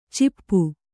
♪ cippu